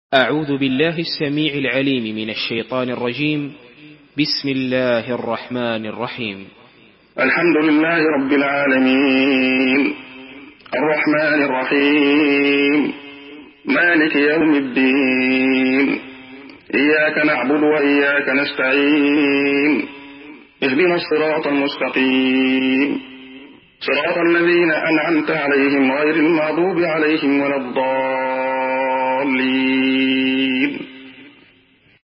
سورة الفاتحة MP3 بصوت عبد الله خياط برواية حفص
مرتل